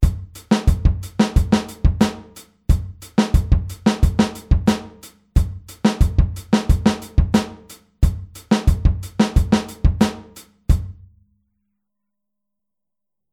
Anders als die Wechselschläge bei 16tel spielt die rechte Hand durchgängig alle 8tel.
Groove17-8tel.mp3